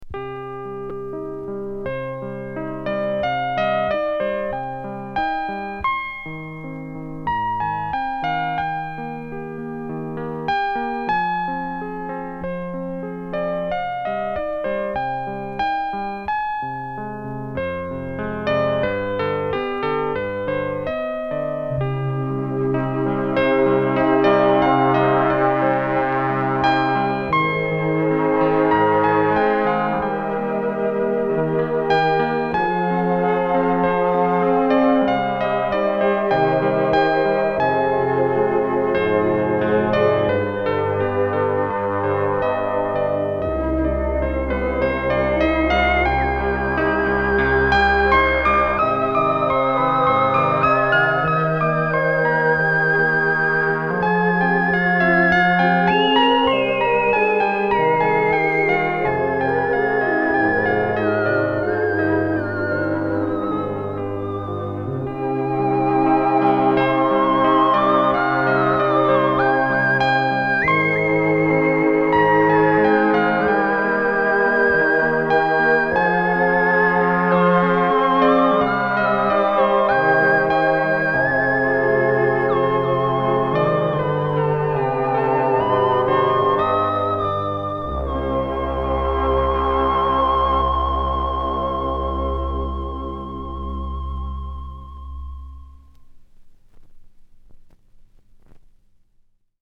Электронная музыка